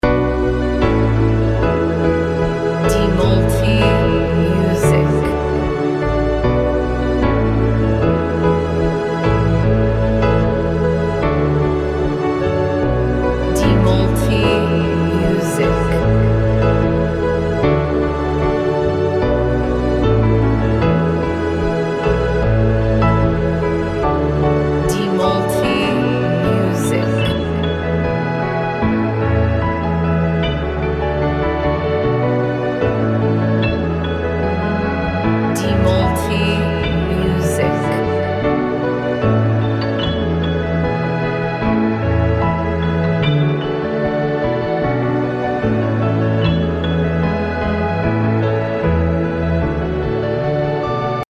Modern Piano Instrumental
Ada keindahan dalam kepergian yang lembut.